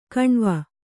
♪ kaṇva